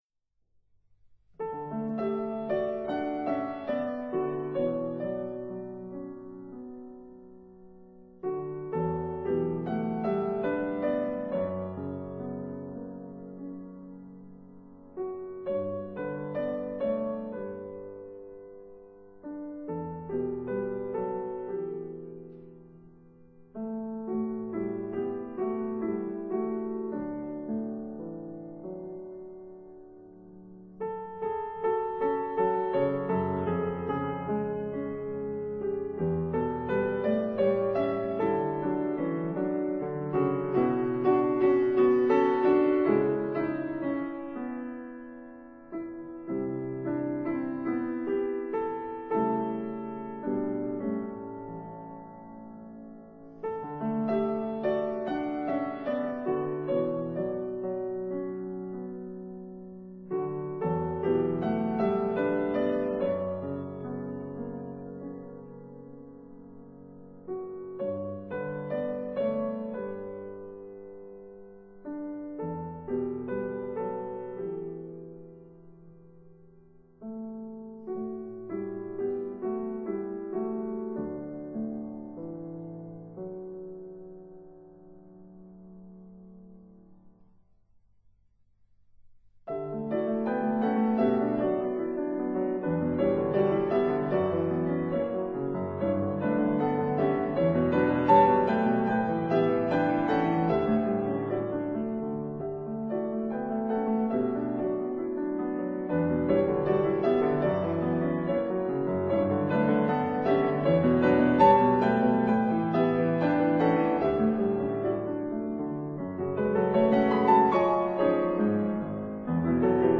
piano Date